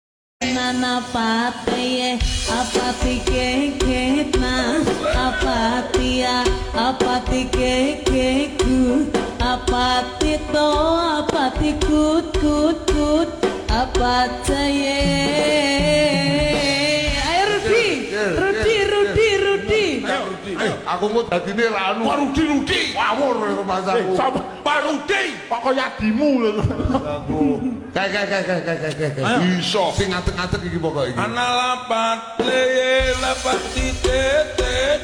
Nepali Songs
(Slowed + Reverb)